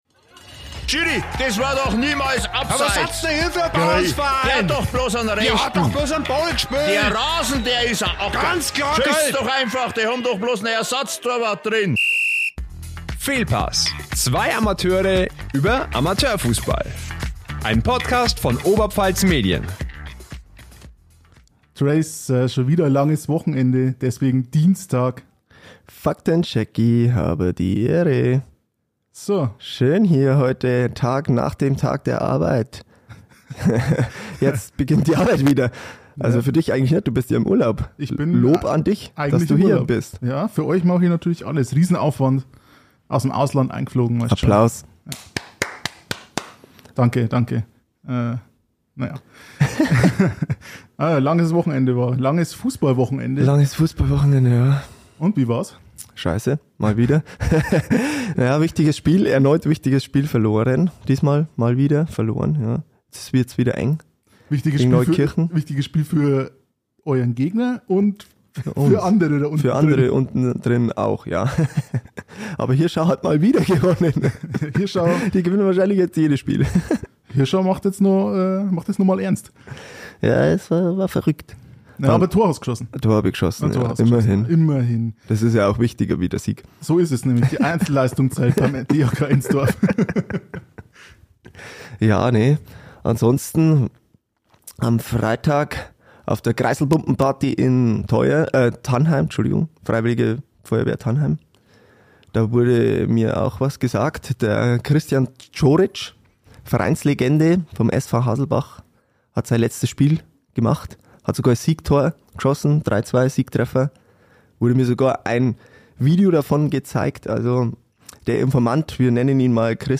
Wir besprechen die feststehenden Meister und Pokalsieger. Mit Sprachnachrichten vom TuS Rosenberg, FV Vilseck und SC Luhe-Wildenau.